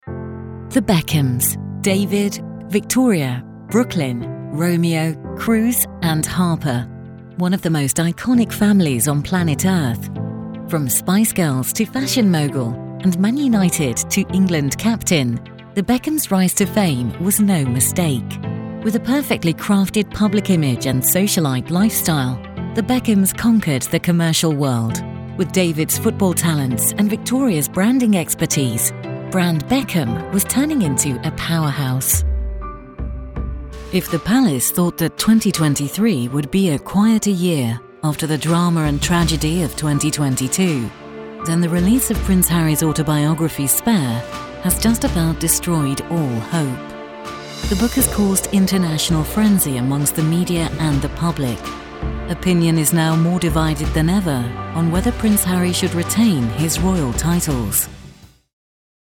Dokumentarfilme
Meine Stimme wird als warm mit sanfter Würde beschrieben – ich liebe es, tiefgründige und modulierte Erzählungen vorzutragen – obwohl ich definitiv auch etwas mehr Abwechslung in lustige und spritzigere Lesungen bringen kann.
Neumann TLM103 Kondensatormikrofon
Heimstudio mit speziell gebauter schwebender Isolationskabine